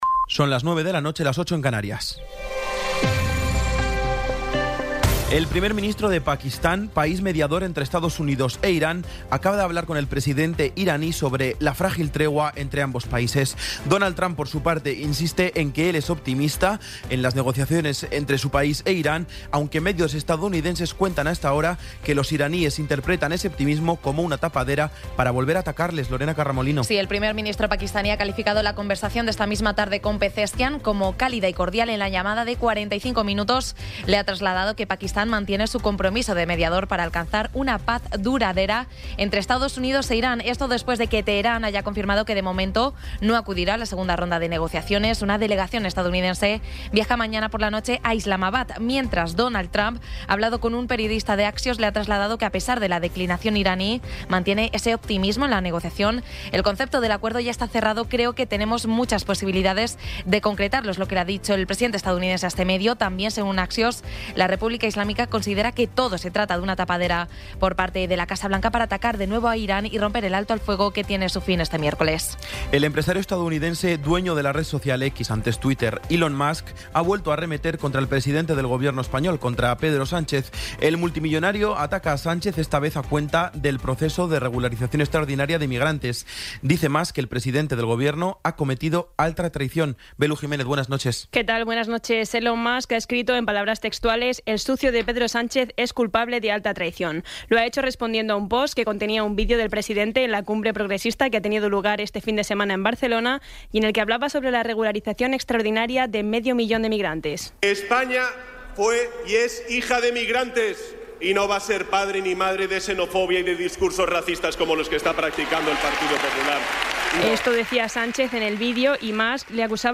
Resumen informativo con las noticias más destacadas del 19 de abril de 2026 a las nueve de la noche.